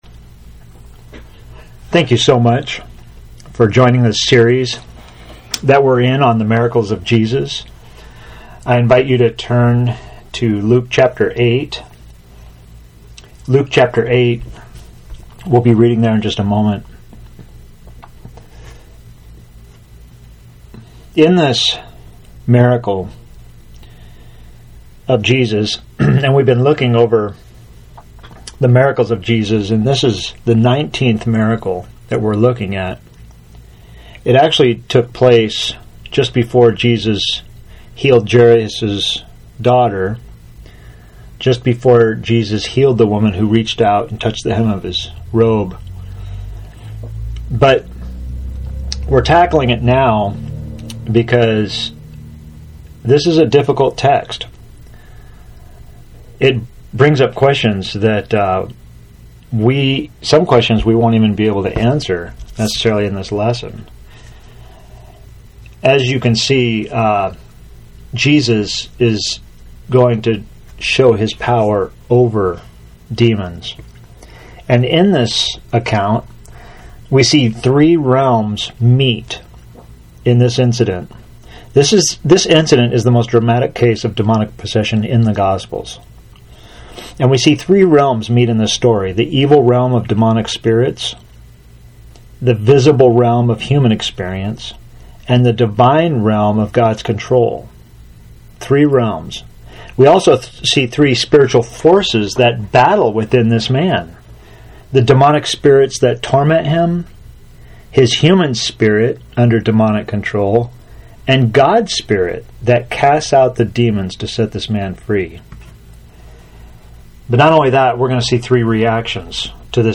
Sermon for August 9, 2020 – AUDIO | TEXT PDF Setting the Captives Free Share this: Share on X (Opens in new window) X Share on Facebook (Opens in new window) Facebook Like Loading...